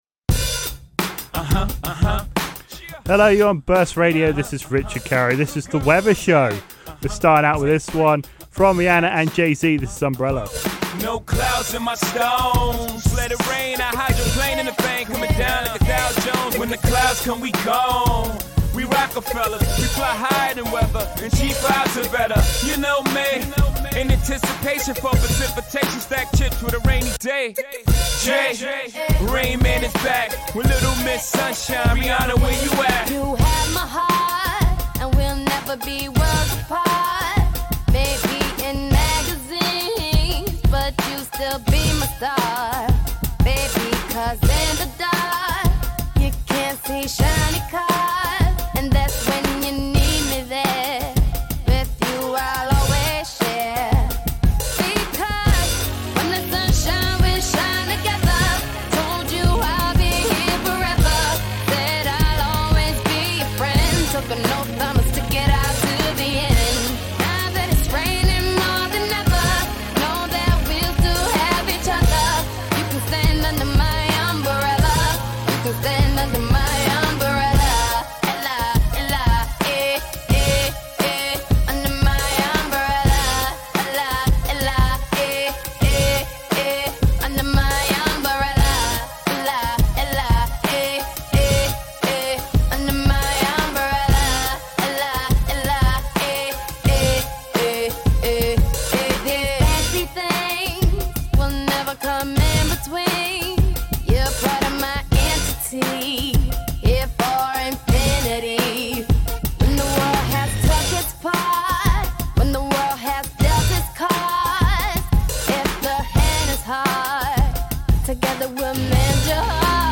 There’s a song for almost every weather. The show also discusses Britain’s obsession of talking about the weather and traditions based around different weather conditions.